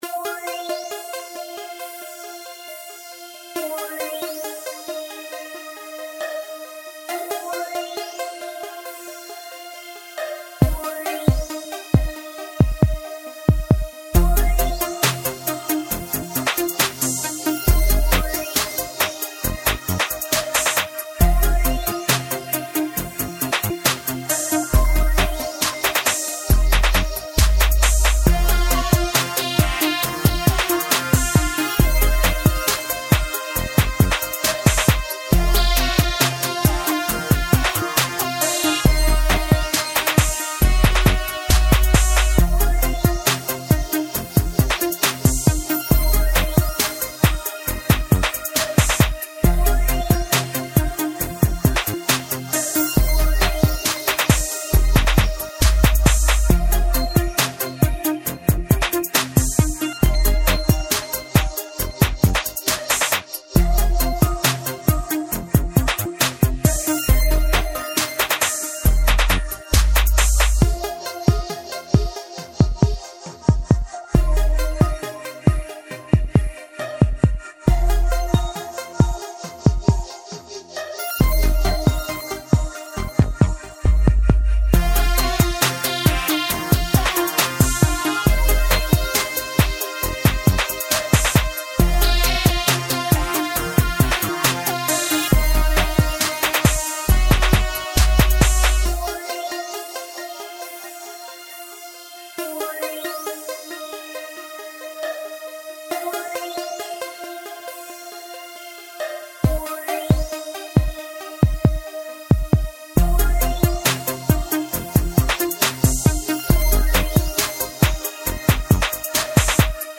Fast paced and ready for action….